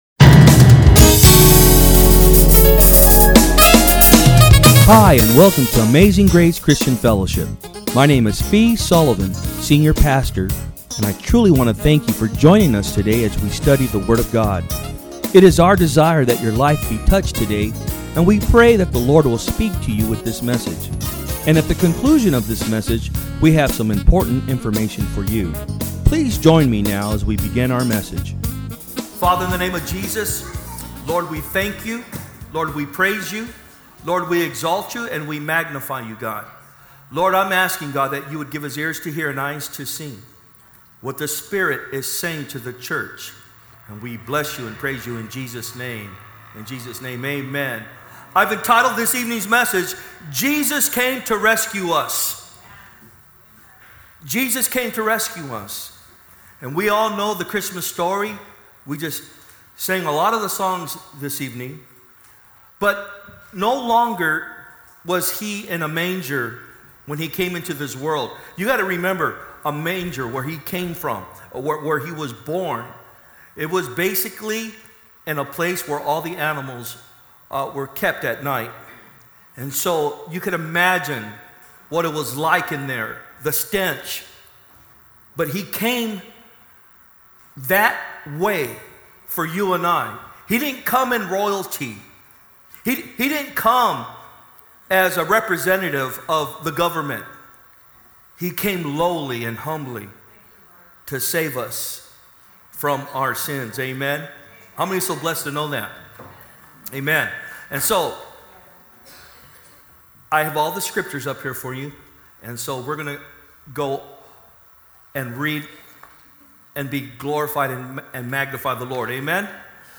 From Service: "Sunday Pm"
Amazing Grace will be having its annual Christmas Service and Dinner. The Amazing Grace Worship Team will be singing the Songs of Christmas.